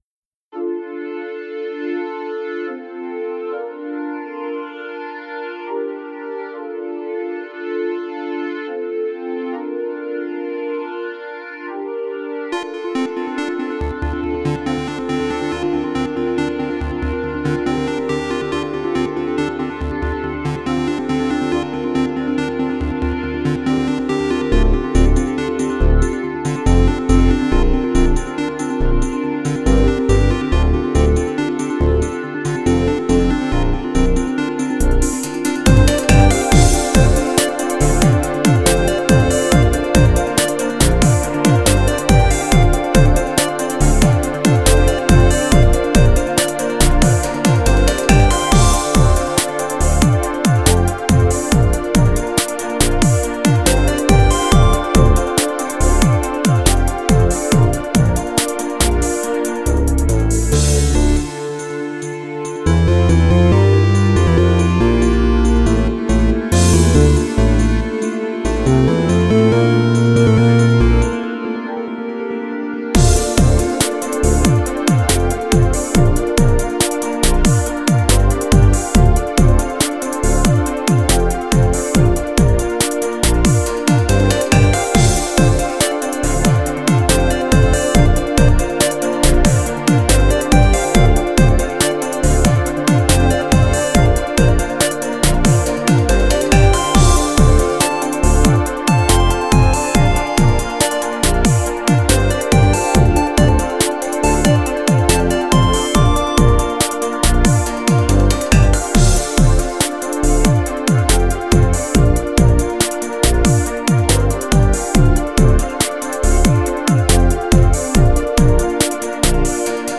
Ah, synthpop! Ah, 7/8!